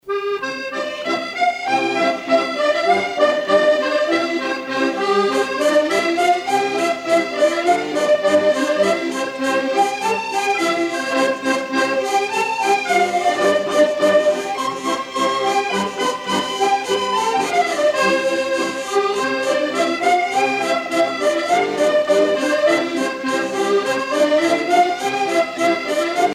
danse : polka des bébés ou badoise
Pièce musicale éditée